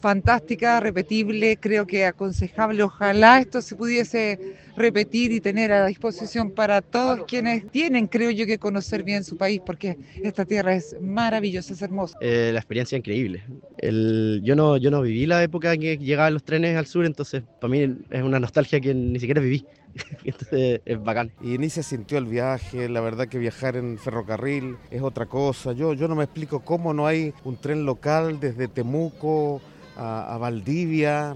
Al llegar a Antilhue, los pasajeros conversaron con Radio Bío Bío y reconocieron que fue una experiencia que volverían a repetir.